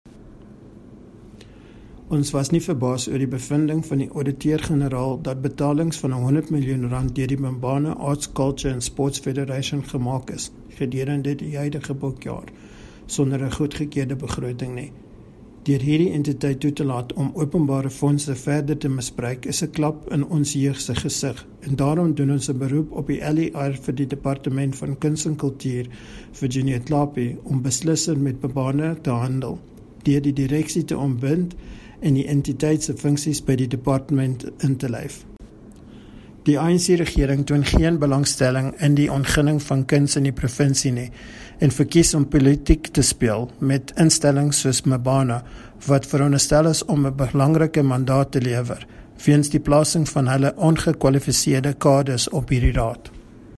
Note to Editors: Kindly find attached soundbites in
Afrikaans by DA North West Spokesperson on Arts and Culture, Gavin Edwards.